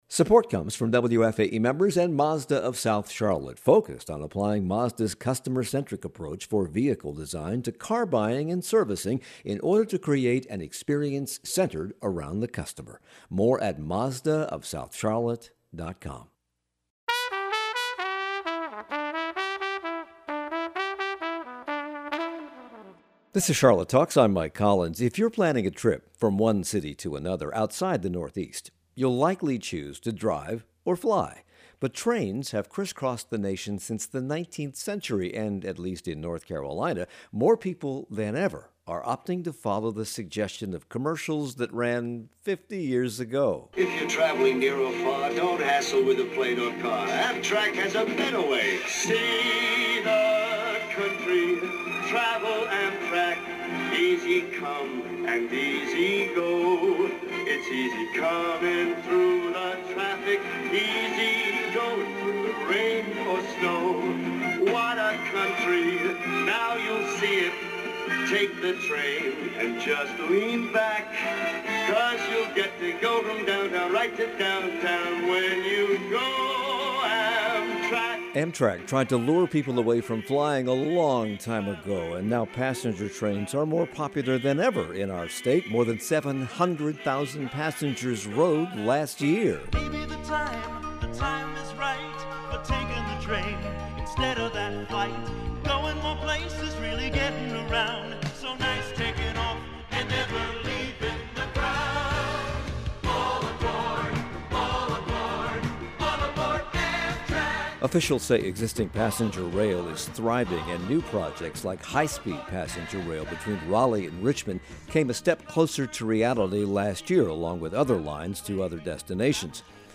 The future of passenger rail in NC. A conversation with Mark Jerrell, chair of the Mecklenburg County Commission.